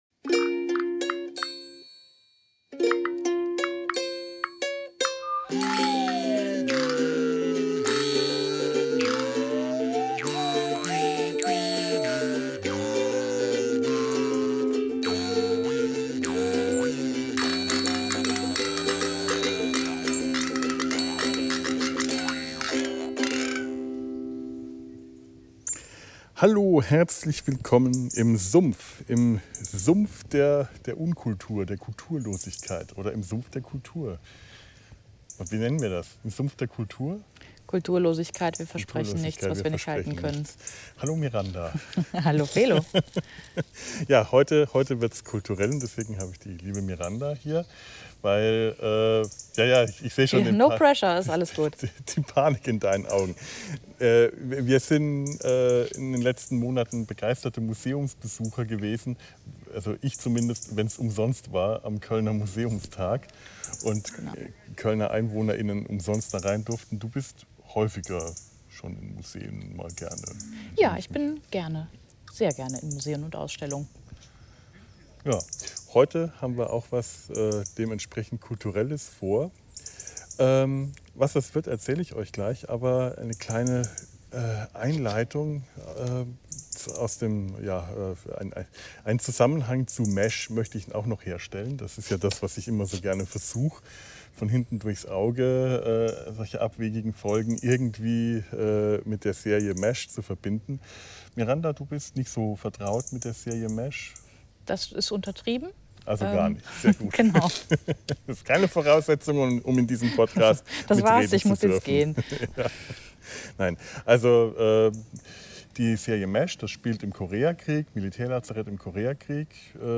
Staffel von M*A*S*H ein hauch von Kultur in den Sumpf eingezogen - wir bemühen uns heute in dieser Folge auch einen Hauch von Kultur in den Sumpf-Podcast einziehen zu lassen: Wir machen einen Spaziergang durch den Stammheimer Schlosspark, einen der zwei Kölner Skulpturen-Parks.